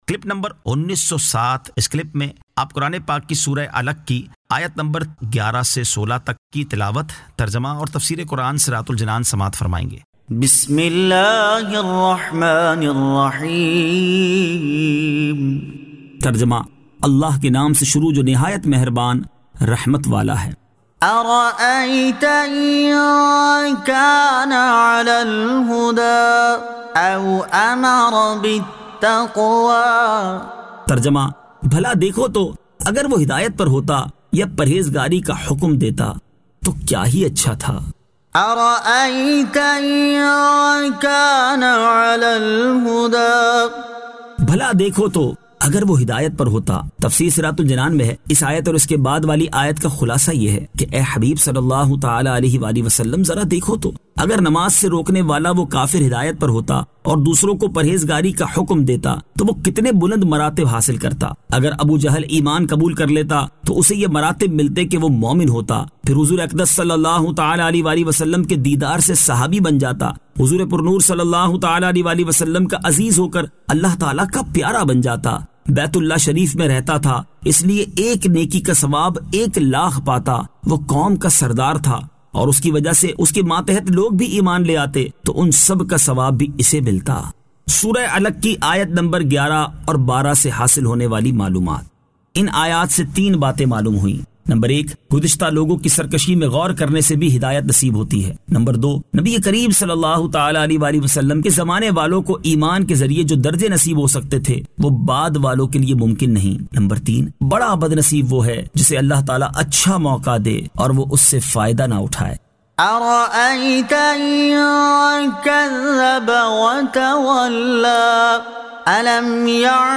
Surah Al-Alaq 11 To 16 Tilawat , Tarjama , Tafseer